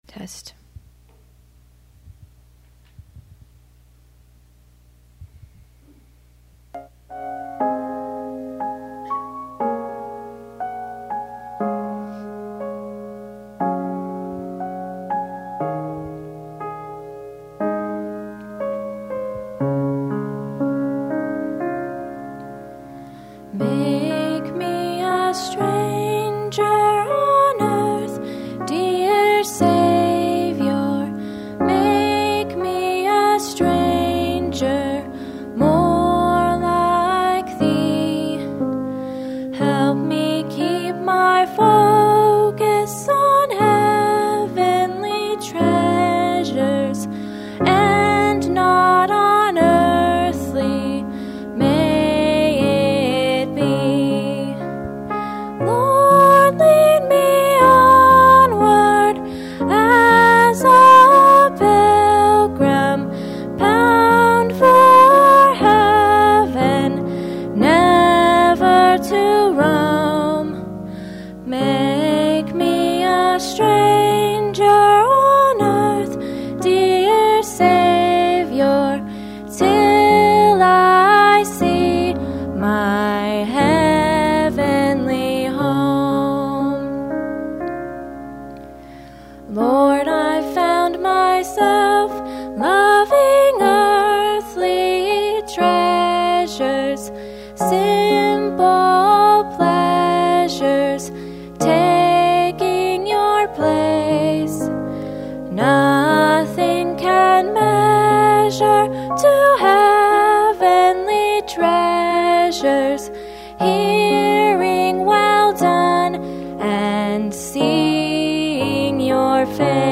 Special Music
Message